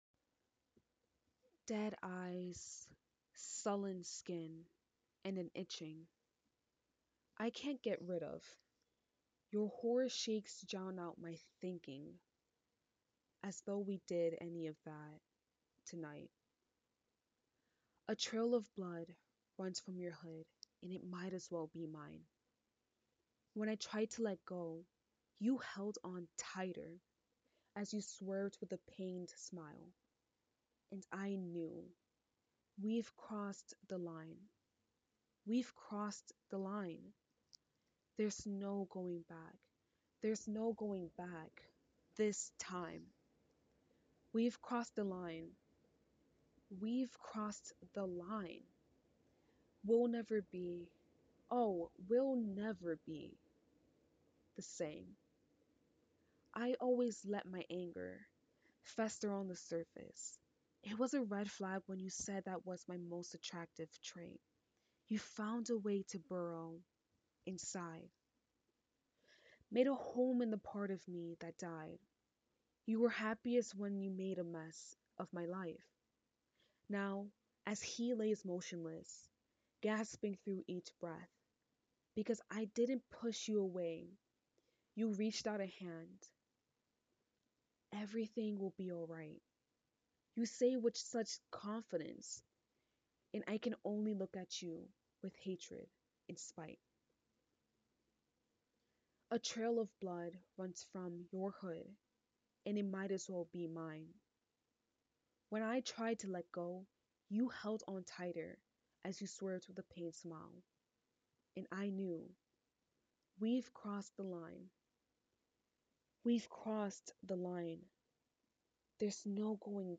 spoke word (demo)